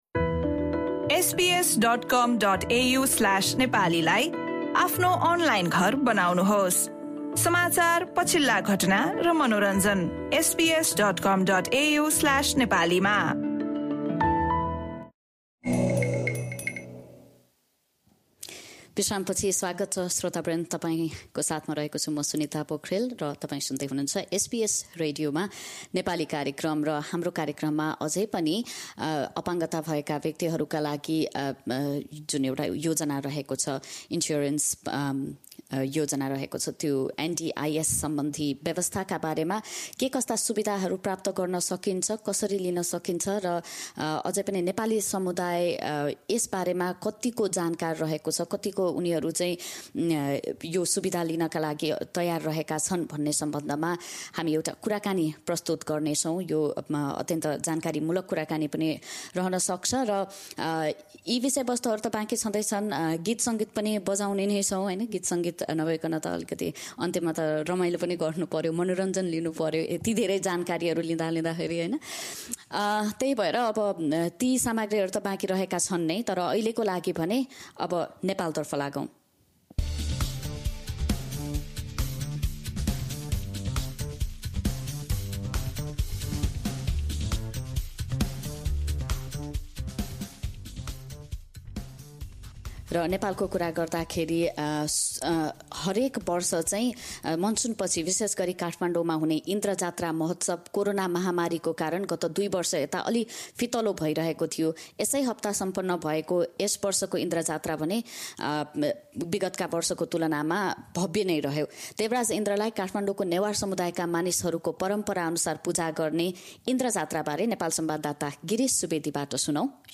नेपालमा इन्द्रजात्रा लगायतका कुनै सामाजिक कार्यको नाममा भिडभाड नगर्न र भेला भएका समूहले पनि भौतिक दुरी कायम राख्न अनिवार्य रहेको कुरा बारम्बार भनेता पनि यसको पालना भएको खासै देखिँदैन। इन्द्रजात्राको सांस्कृतिक महत्त्व र महामारीका बेला नेपालीहरूले सरकारी नियमहरूको बेवास्ता गरेको बारे काठमाण्डूबाट